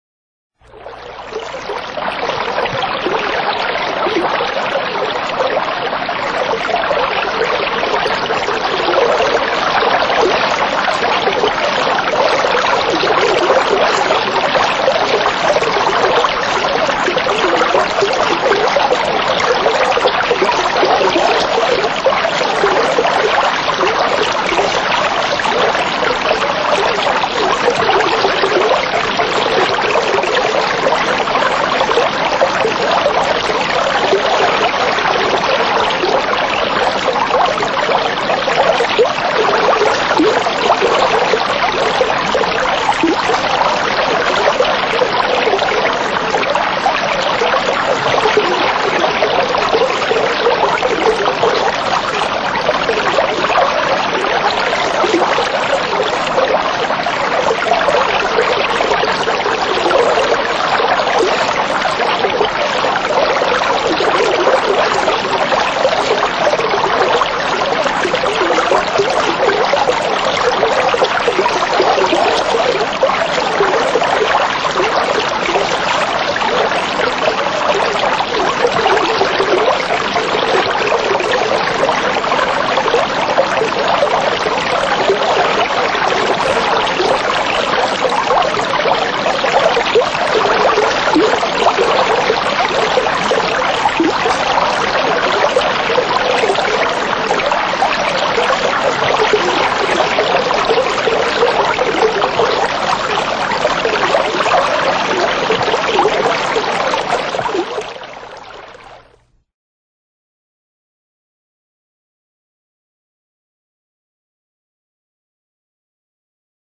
Булькающий звук воды в аквариуме